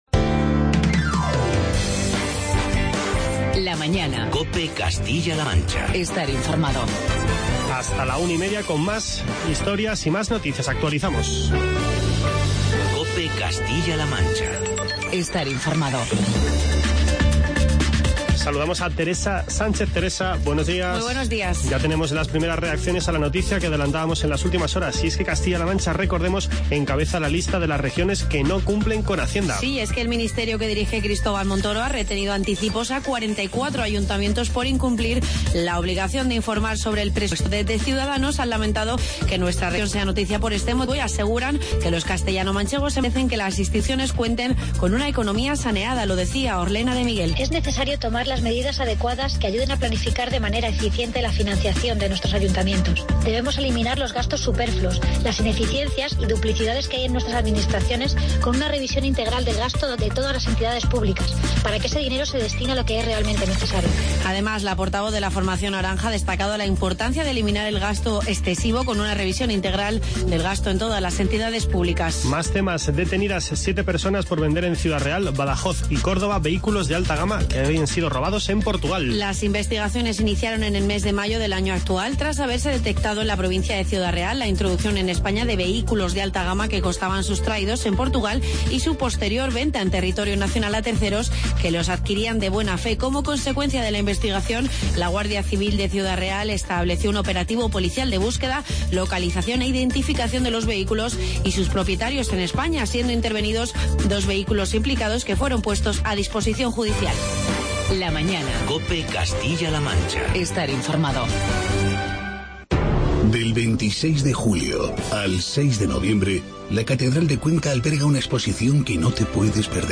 Entrevista con la diputada socialista